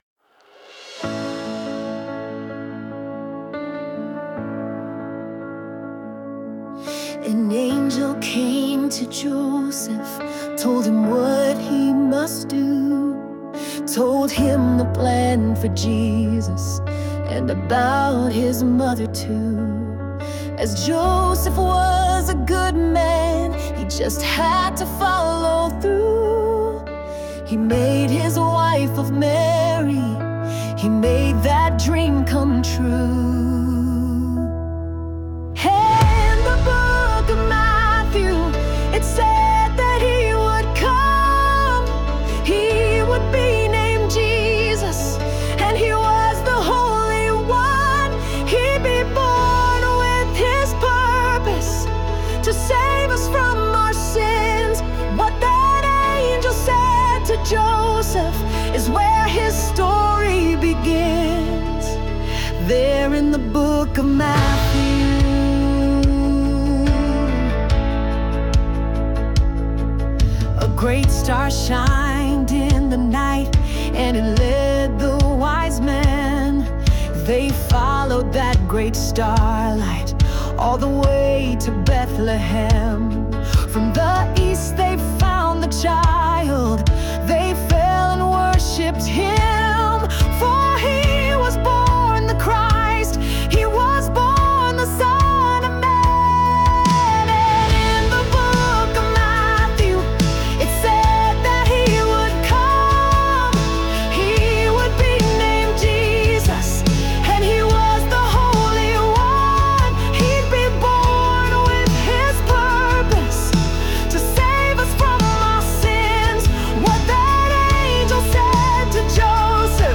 Complete Christian Song